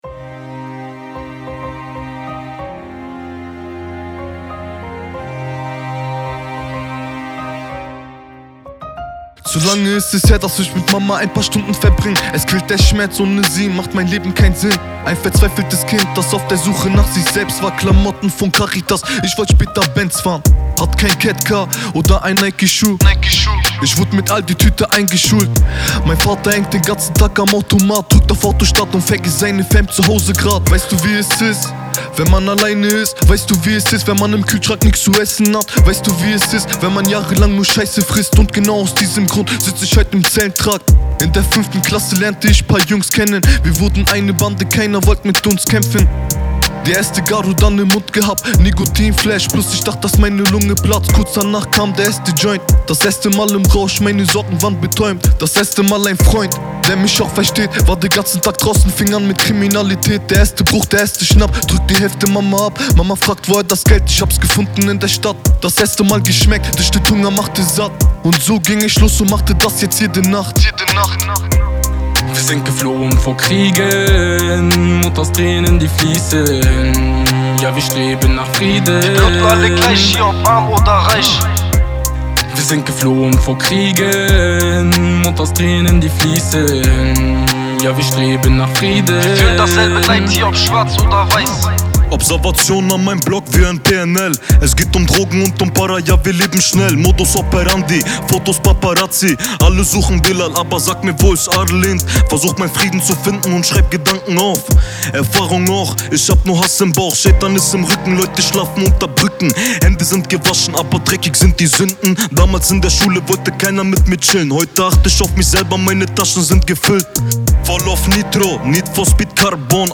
Freestyle rappen
Klavier und Geige sind am meisten gefragt.
Der Beat war ein Vierteltakt-Beat.
Diesen verfeinerten wir mit Klavier- und einem Schwert-Sound.
Als wir alle ein paar Texte geschrieben hatten, haben wir sie nacheinander aufgenommen und mit Effekten ausgestaltet.